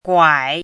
chinese-voice - 汉字语音库
guai3.mp3